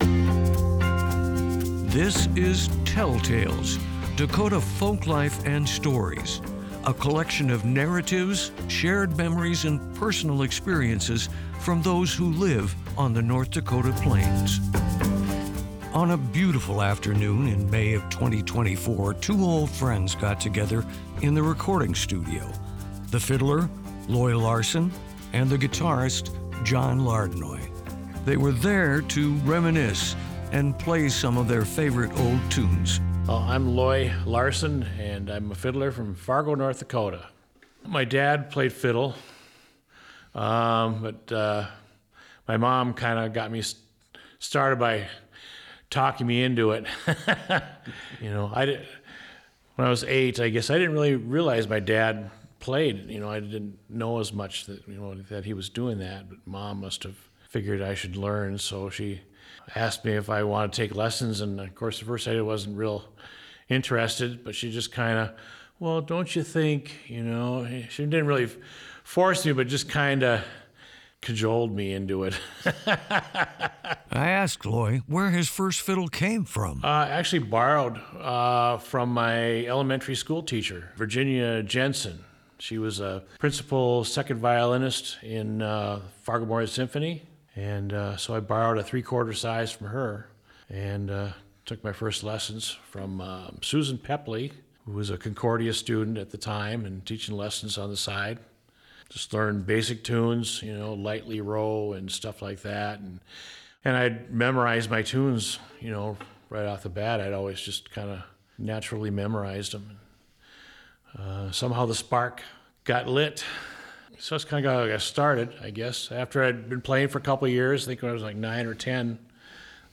fiddle
guitarist
play some of their favorite old tunes together